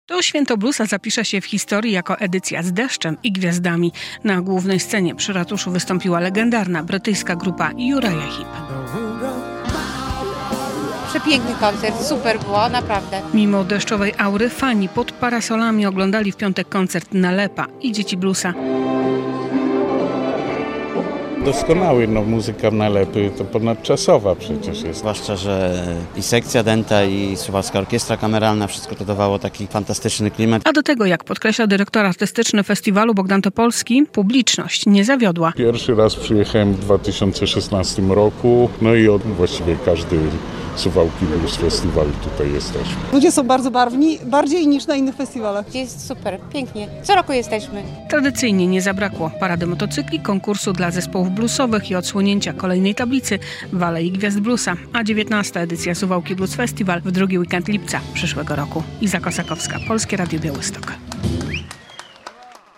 18. Suwałki Blues Festiwal - podsumowanie